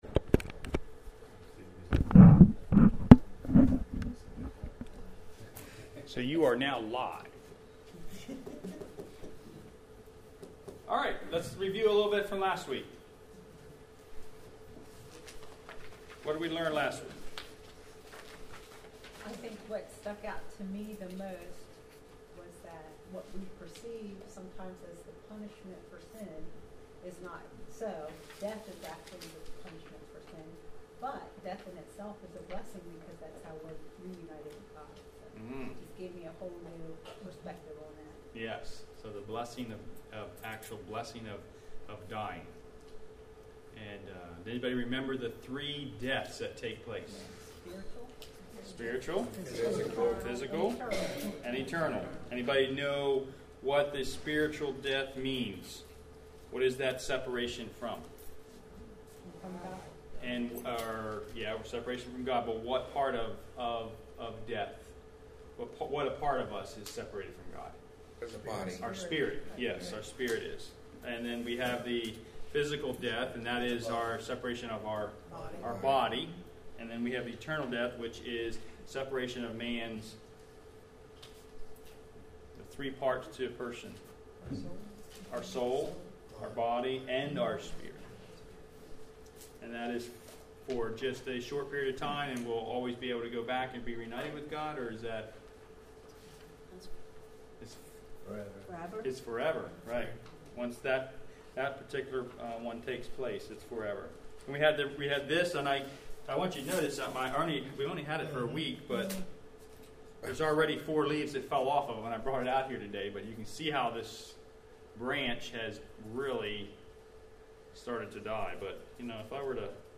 God creates Eve. You are here: Home Sermons Archived Sermons Firm Foundations Lesson 7